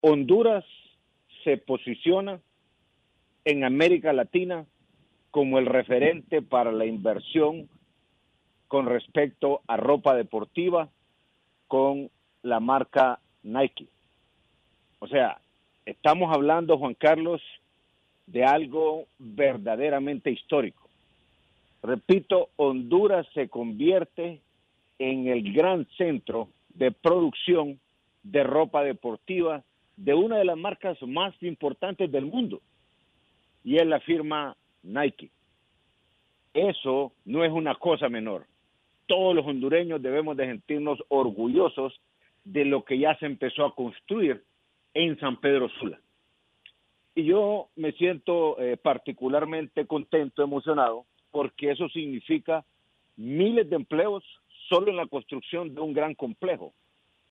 Declaraciones del mandatario, Juan Orlando Hernández